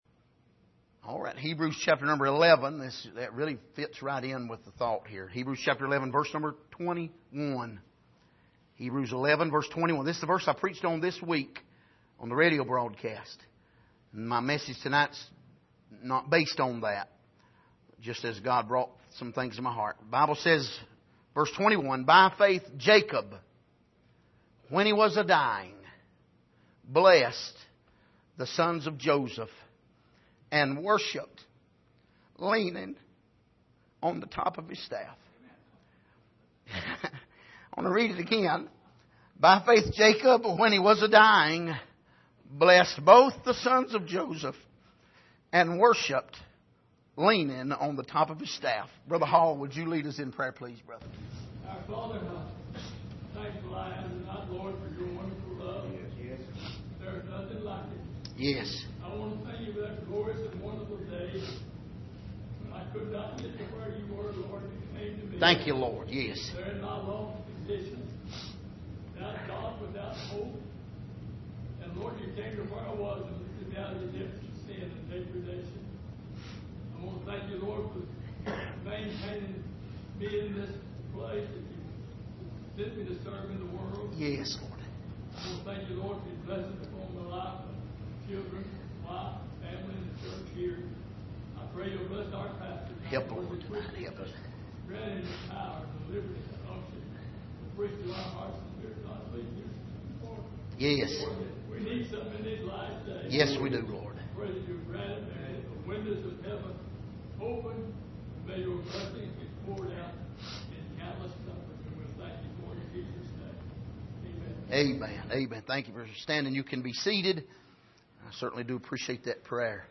Passage: Hebrews 11:21 Service: Sunday Evening